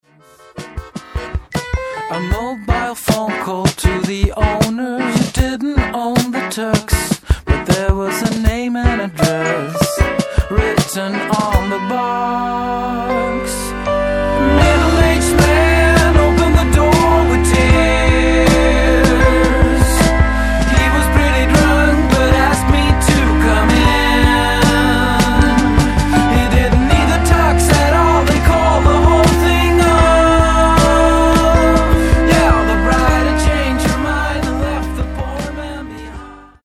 Guitar Pop/Swedish